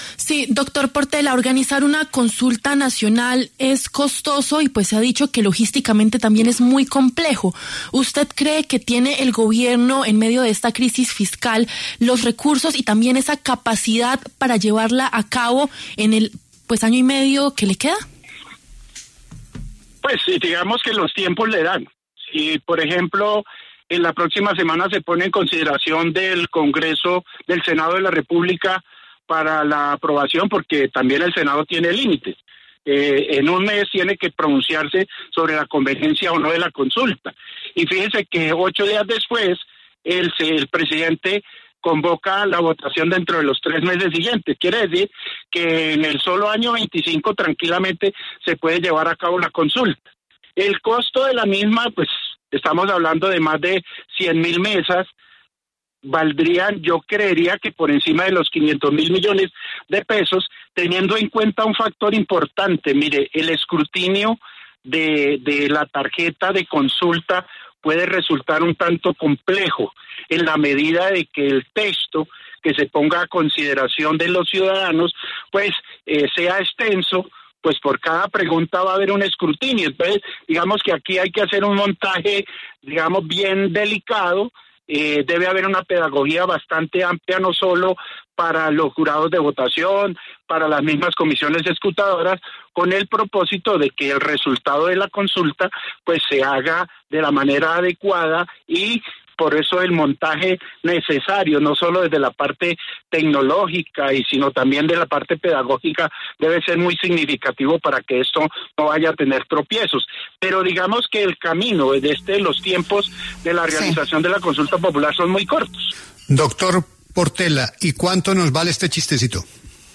En ese sentido, el exregistrador y experto en derecho electoral Alfonso Portela, habló en los micrófonos de La W, con Julio Sánchez Cristo, para dar detalles de este tema y explicar a fondo en qué consiste.
Alfonso Portela, experto en derecho electoral, habla en La W sobre la consulta popular propuesta por el presidente Gustavo Petro